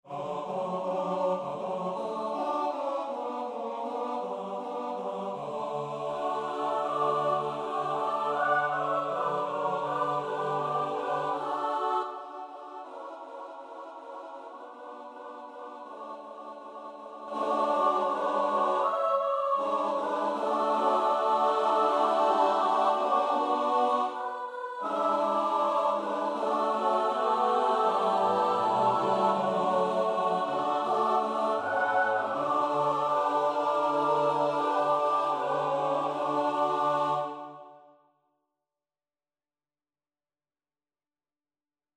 Number of voices: 4vv Voicing: SATB Genre: Secular, Partsong
Language: German Instruments: A cappella